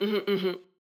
VO_ALL_Interjection_15.ogg